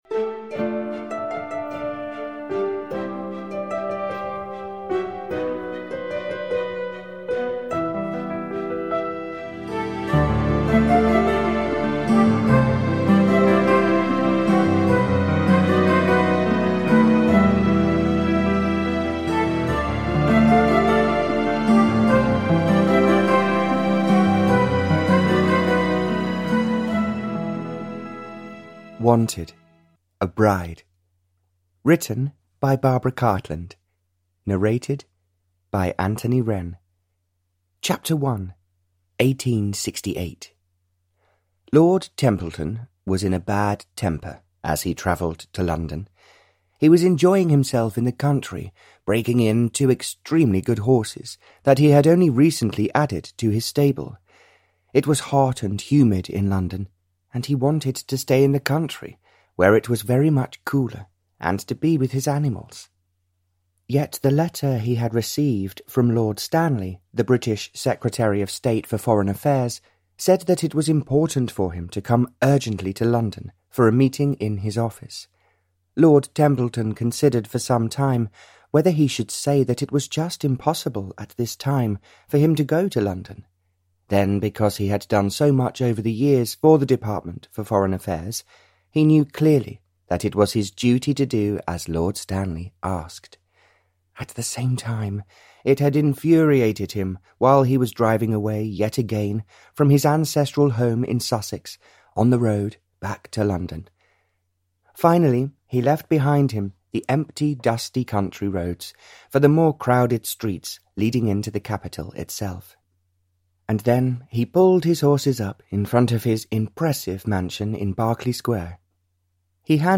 Wanted - A Bride (Barbara Cartland's Pink Collection 125) – Ljudbok – Laddas ner